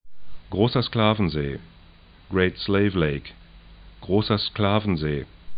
'gro:sɐ 'skla:vən-ze: